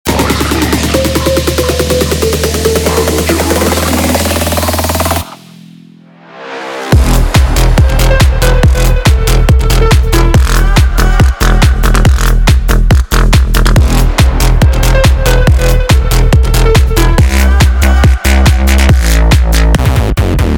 • Качество: 192, Stereo
ритмичные
Electronic
мощные басы
Bass House
качающие
клубняк
цикличные